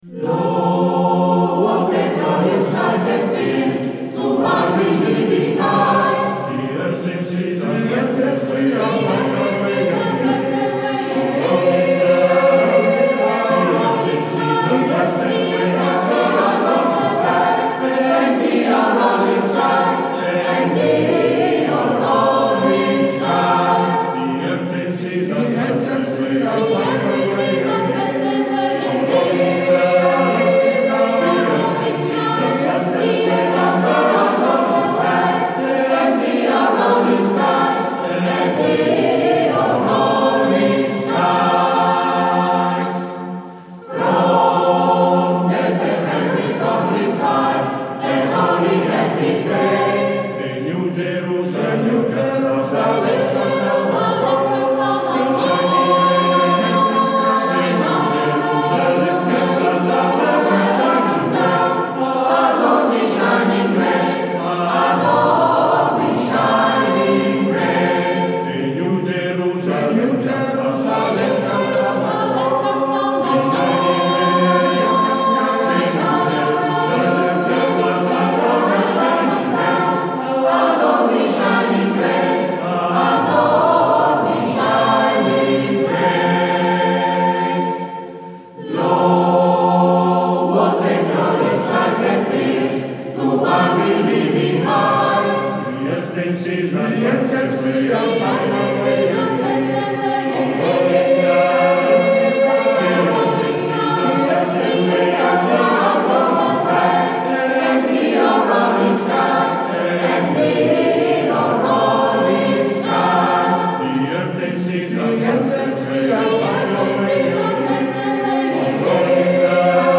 Esecutore: Coro CAI Melegnano